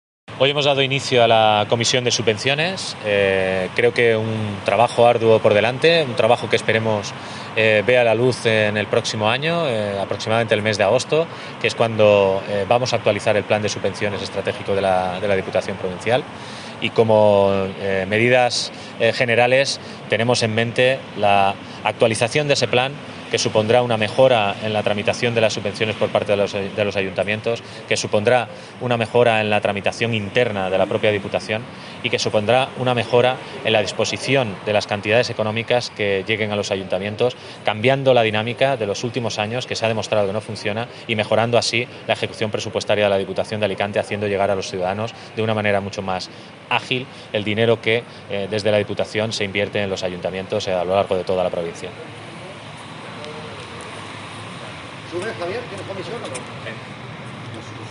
El diputado de Infraestructuras, Javier Gutiérrez, quien preside este nuevo órgano, ha explicado que una de las principales iniciativas consensuadas es la disposición de las subvenciones.
Corte-Javier-Gutiérrez-Subvenciones-1.mp3